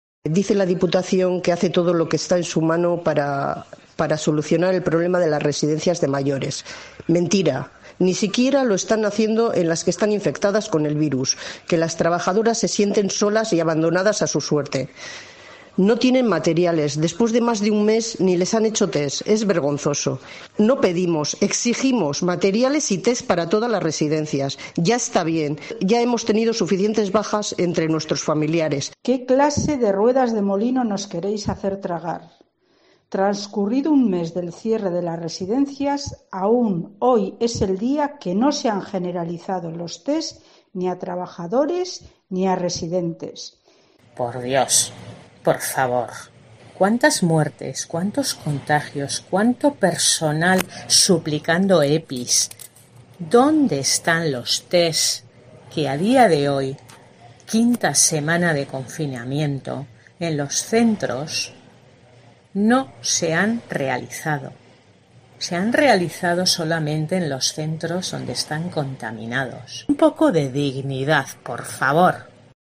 familiares de la Asociación Gipuzkoako Senideak